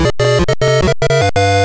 Self-captured from the Sharp X1 version.